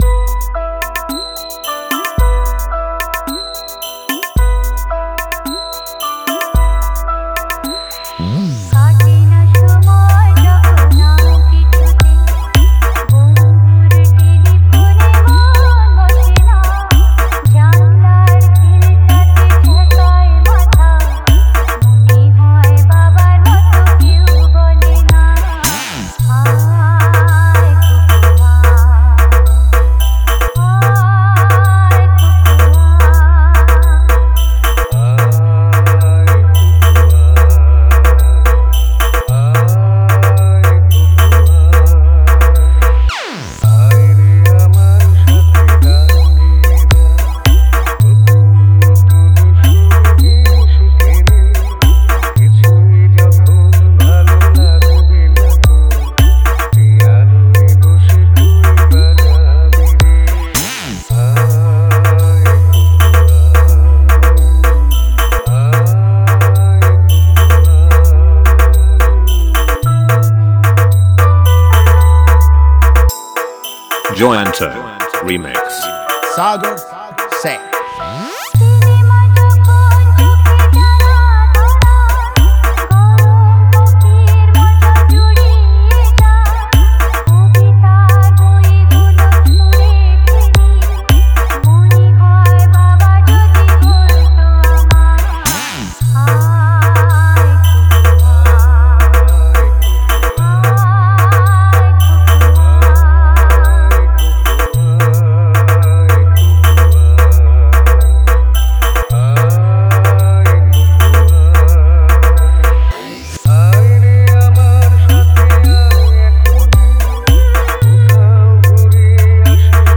পুজোর স্পেশাল গান